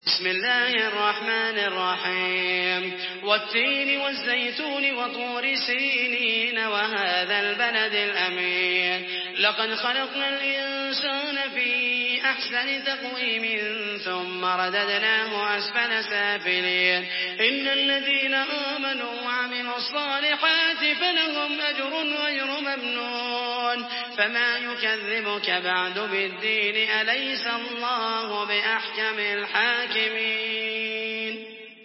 Surah At-Tin MP3 by Muhammed al Mohaisany in Hafs An Asim narration.
Murattal Hafs An Asim